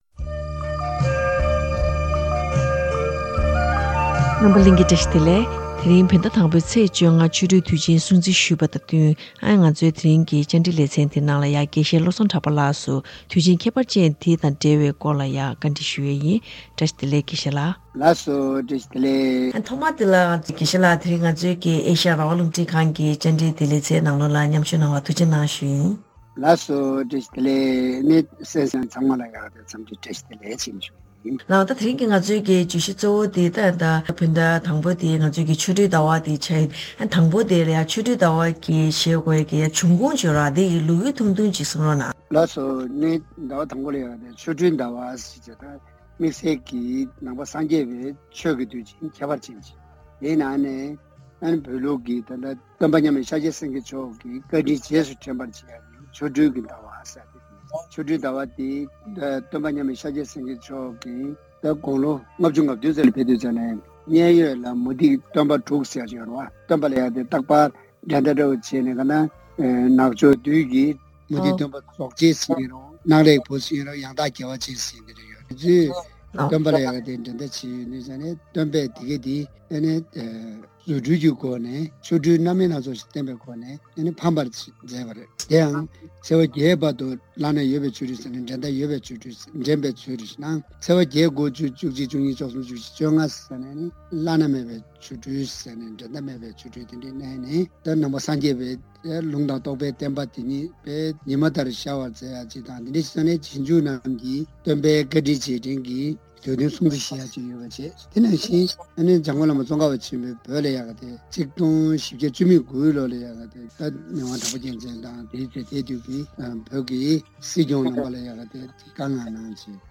གནས་ཚུལ་རྒྱས་པ་